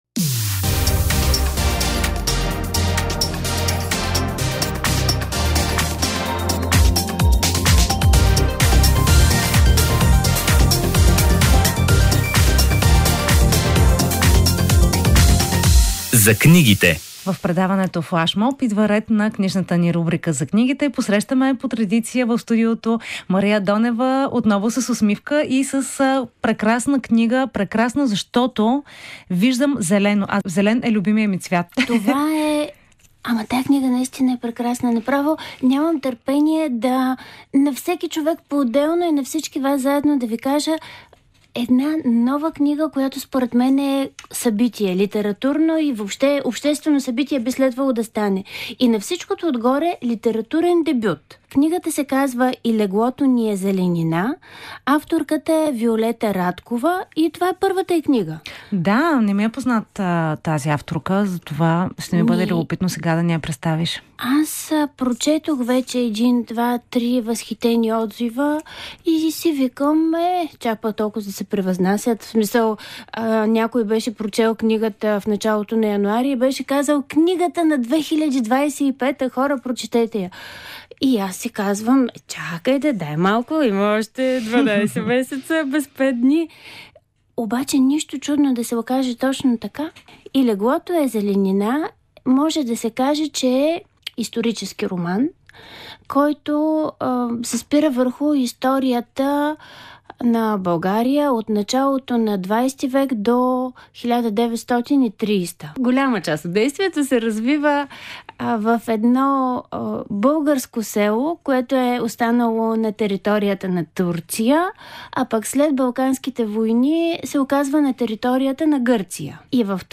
Тя е част от предаването „Флашмоб“, което се излъчва всяка събота от 16 часа по Радио Стара Загора.